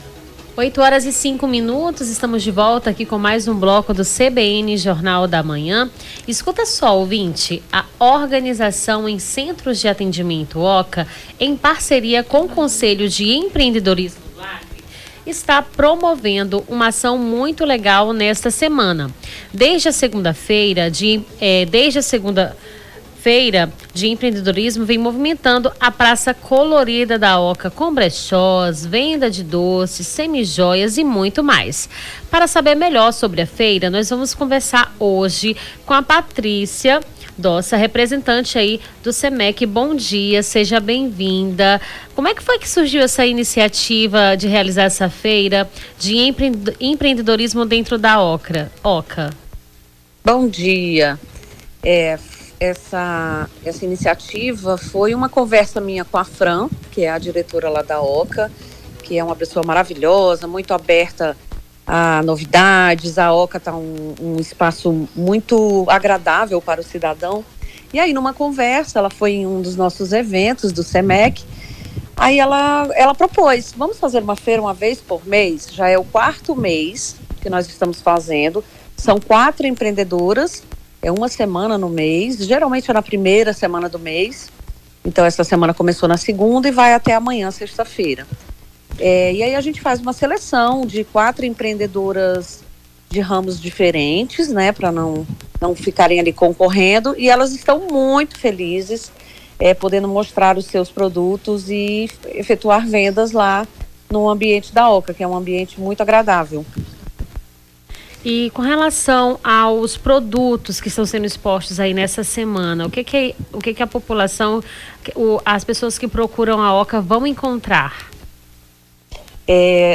Nome do Artista - CENSURA- ENTREVISTA FEIRA EMPREENDEDORISMO (07-11-24).mp3